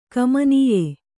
♪ kamanīye